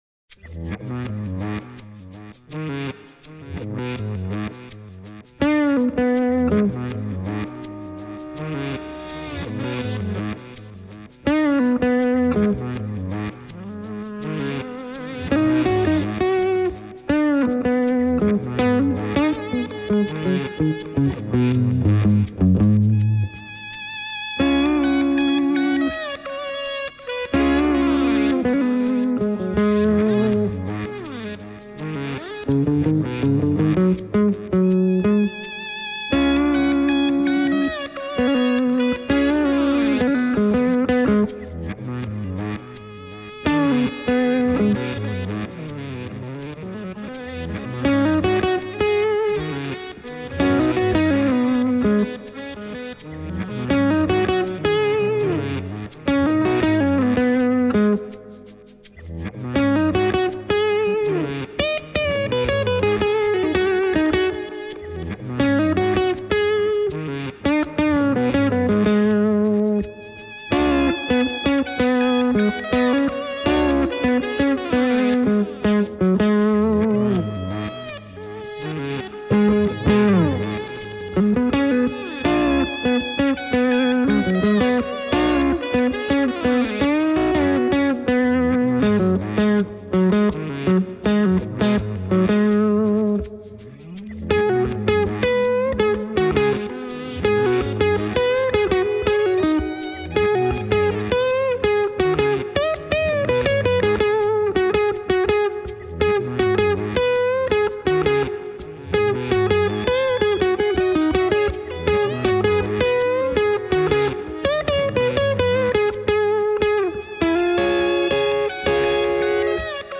Soolokitarana toimii Gibson Les Paul Junior (kitaran tone-potikka oli säädetty hieman alas), kun taas takaperin soivat kitaralinjat on soitettu Gibson Melody Maker SG -mallilla sisään (molemmissa biiseissä kitaramikkinä toimii Shure SM57):
Toisessa demobiisissä Bogner oli ns. täysillä (66 W) ja sen eteen oli kytketty Boss SD-1 -särö ja Joyo JF-37 -chorus. Kaikki kitararaidat on soitettu Flaxwood MTQ Hybrid -kitaralla, joka on kaulahumbuckerilla varustettu Tele-tyylinen soitin (testi tulossa):